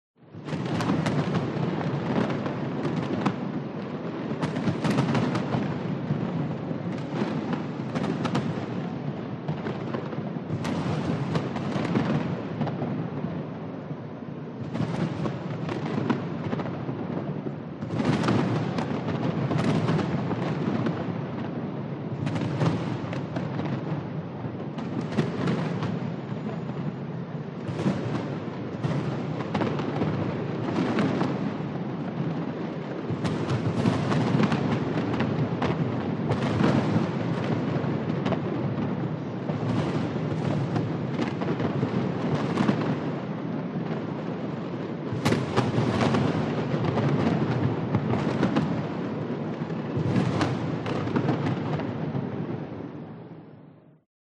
Грандиозный салют, в небе взрывы и трески огней — 54 сек